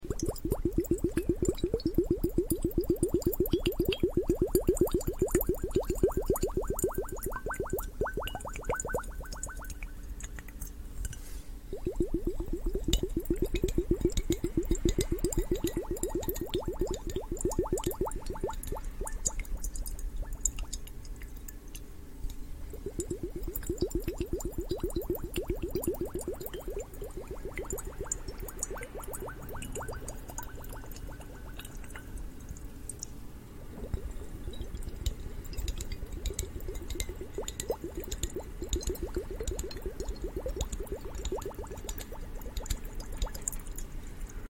Asmr water hourglass, SATISYING water sound effects free download
Asmr water hourglass, SATISYING water sounds